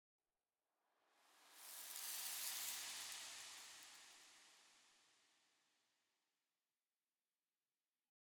Minecraft Version Minecraft Version snapshot Latest Release | Latest Snapshot snapshot / assets / minecraft / sounds / ambient / nether / soulsand_valley / sand2.ogg Compare With Compare With Latest Release | Latest Snapshot